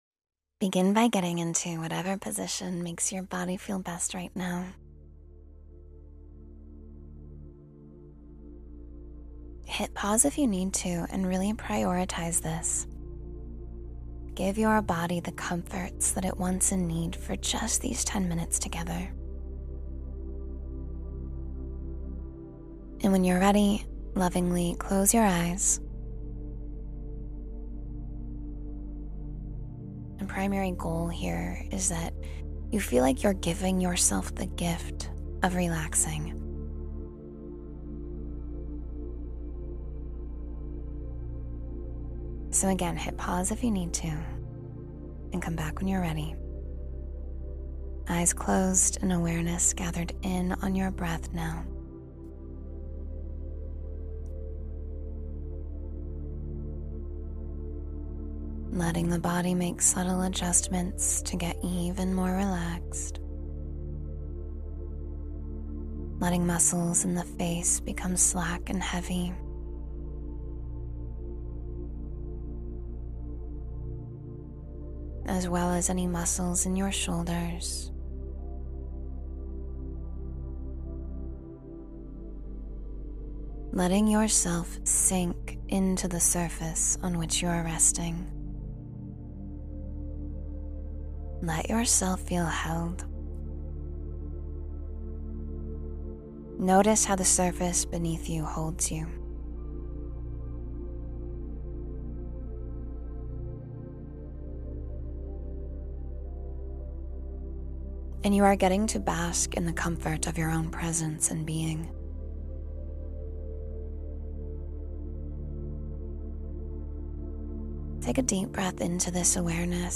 Manifest True Love Through Inner Alignment — Meditation for Heart Healing